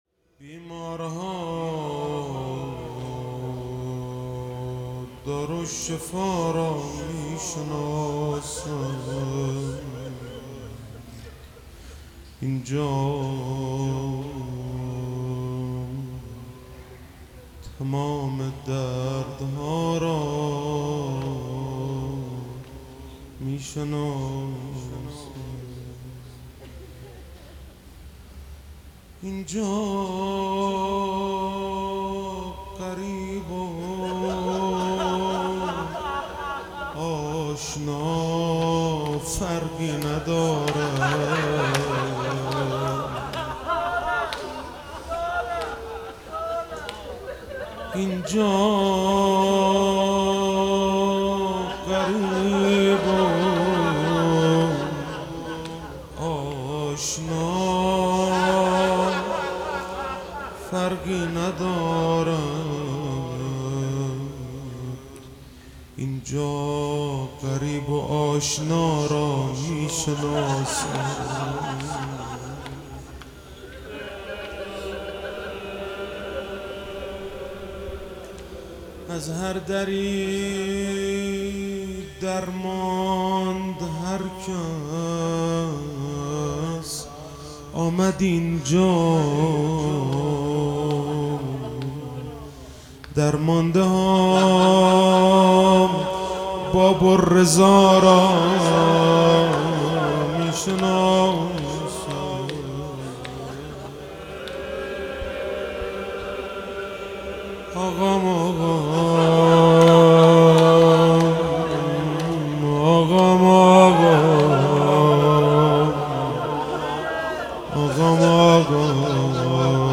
زیارتی امام رضا (ع)